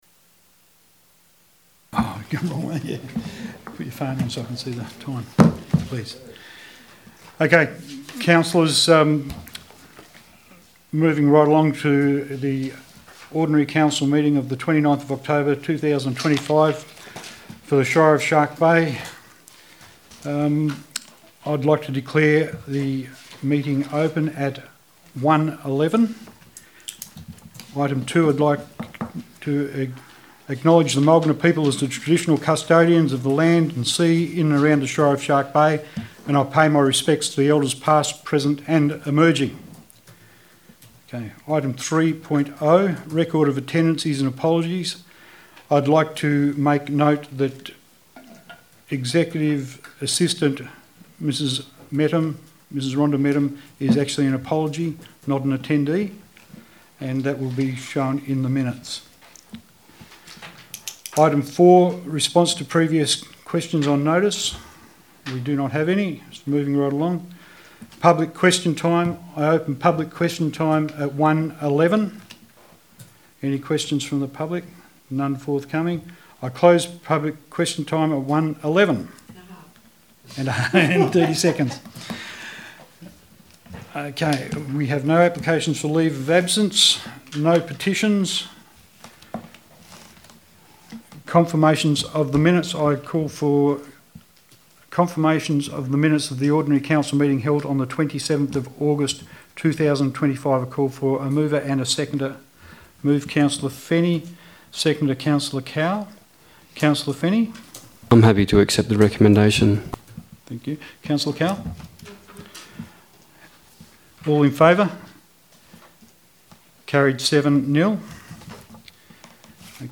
Ordinary Council Meetings
Shire of Shark Bay Council meetings are held at 3pm on the last Wednesday of every month except December when it is held on the third Tuesday.